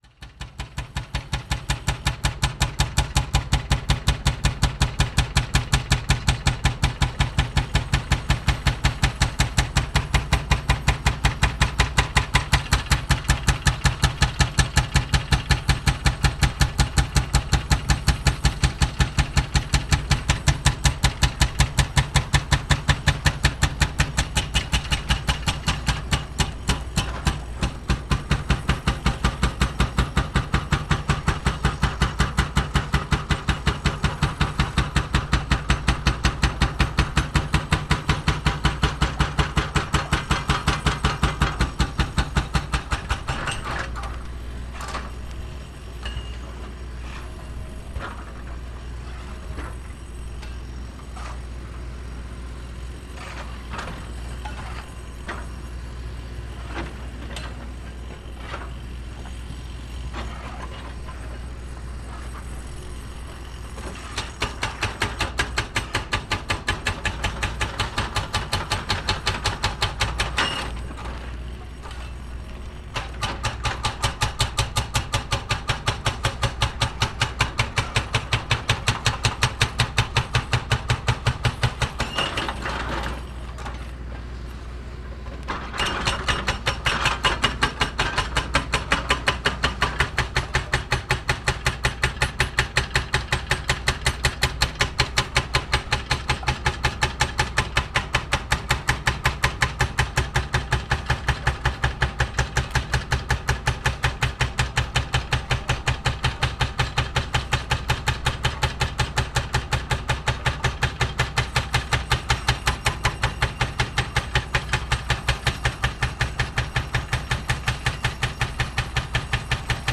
Roadworks on Sea Road
Coastal path roadworks in Westgate-on-Sea, Margate, June 2015.
Part of the Cities and Memory Margate sound map for Dreamland.